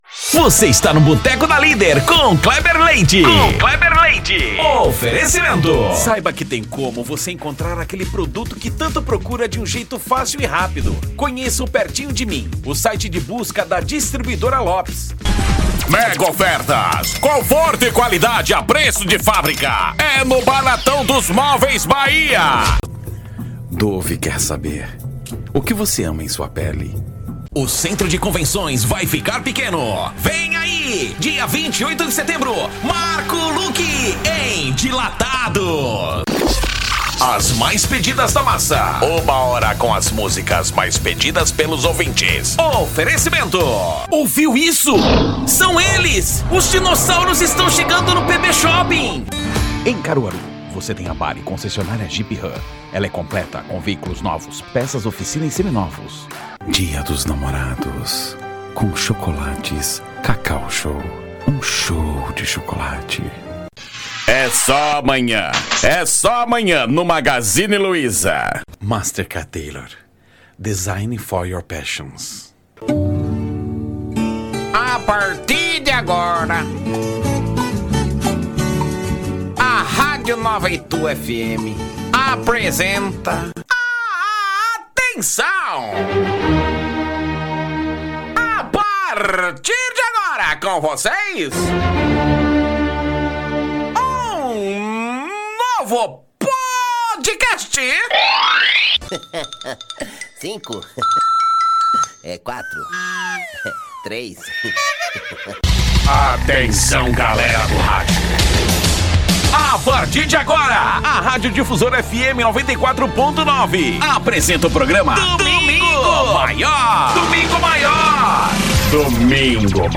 DEMONSTRATIVO SUPERMERCADO, ATACADO,MERCADO E HORTIFRUT:
Animada